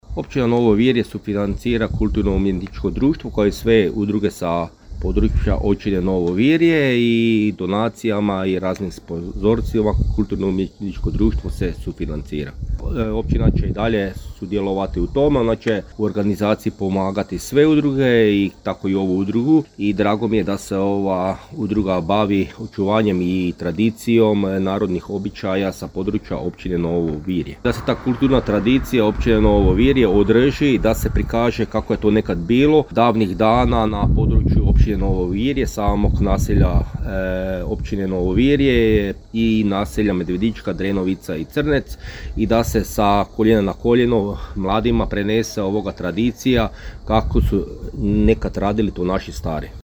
– naglasio je načelnik Općine Novo Virje, Mirko Remetović.